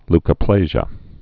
(lkə-plāzhə)